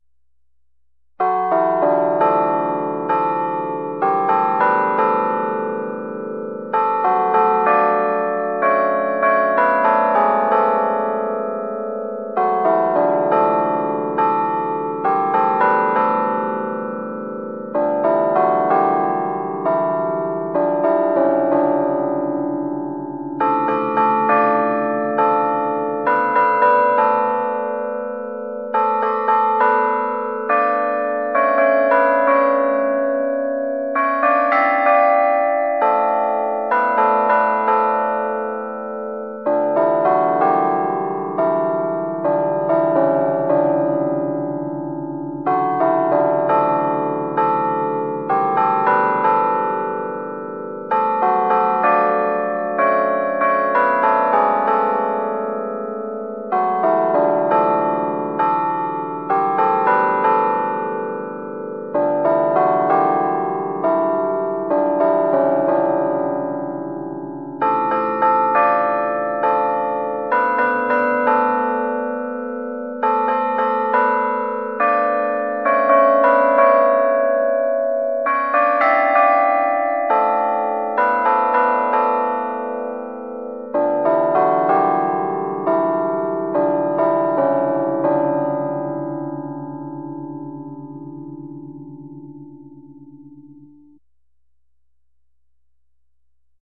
Our carillon controllers use real bell melodies.
• Realistic digital bell sounds that replicate traditional carillons